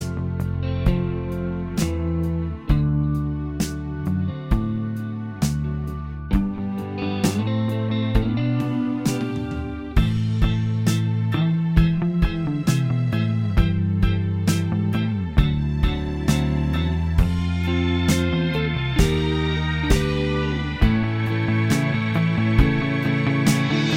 Minus Solo Guitar Pop (1960s) 2:59 Buy £1.50